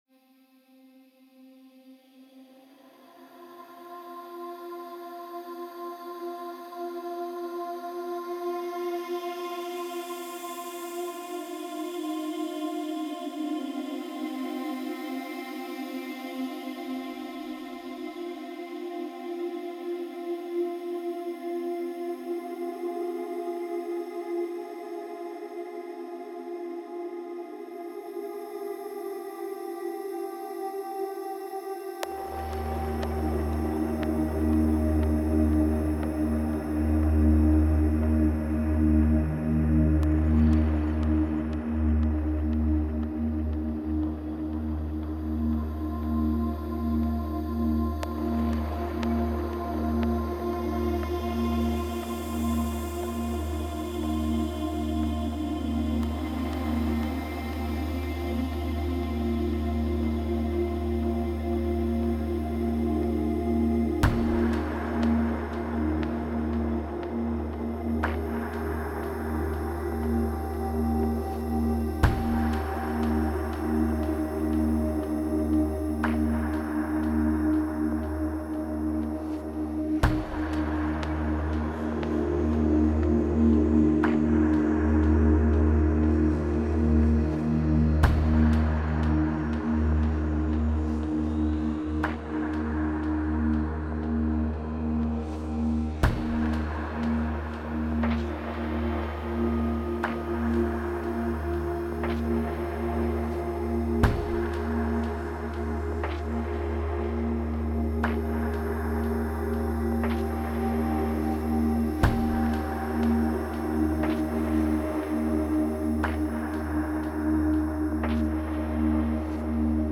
Tags: ambient